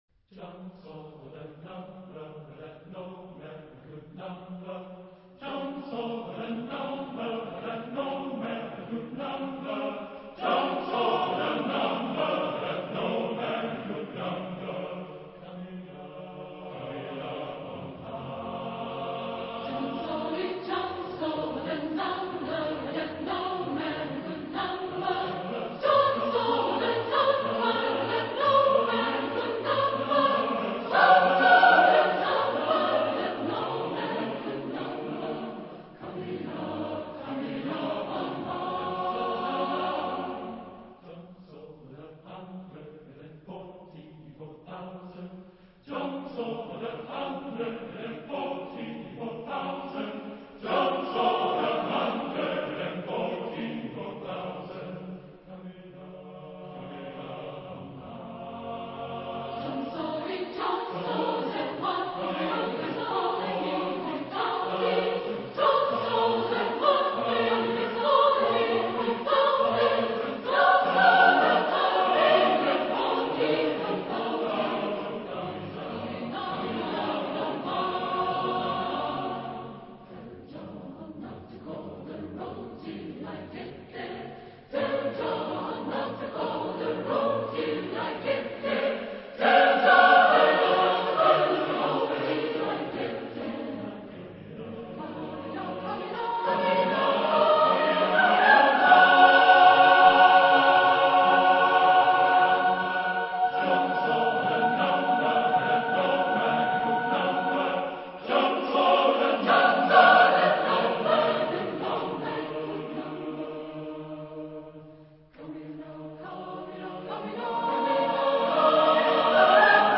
Genre-Style-Forme : Spiritual Afro-Américain ; Sacré
Type de choeur : SATB divisi  (4 voix mixtes )
Tonalité : fa majeur